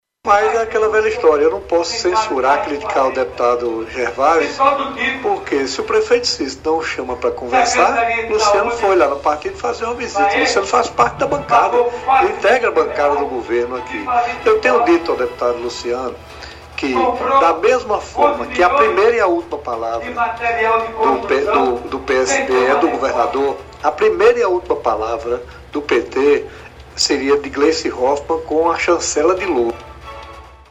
Em entrevista ao programa Arapuan Verdade, da Rádio Arapuan FM, desta terça-feira (24/10), Hervázio afirmou que a última palavra do partido sobre as eleições é do governador João Azevêdo (PSB), e espera que o prefeito de João Pessoa, Cícero Lucena (PP), dialogue mais com a base socialista para manter apoio.
Dep-Hervazio-Bezerra.mp3